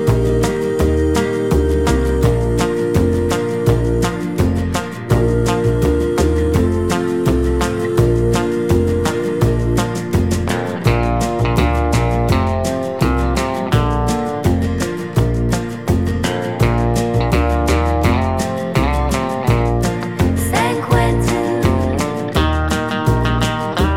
Live Country (Male) 2:26 Buy £1.50